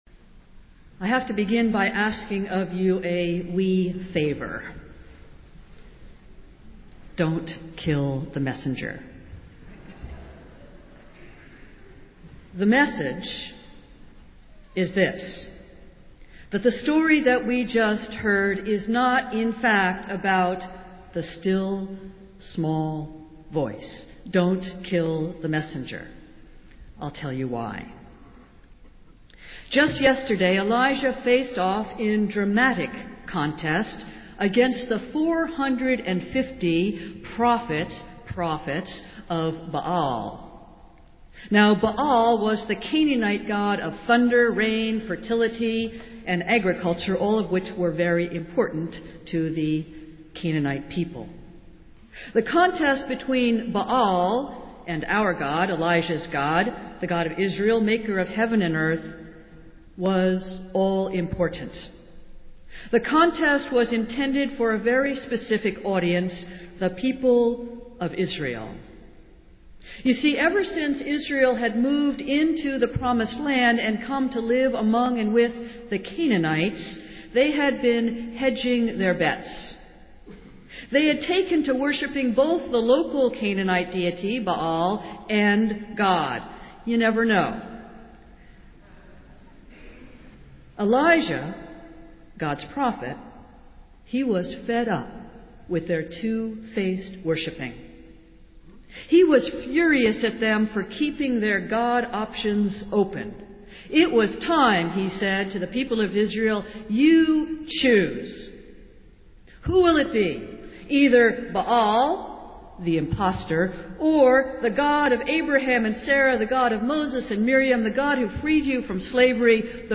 Festival Worship - Fifth Sunday of Easter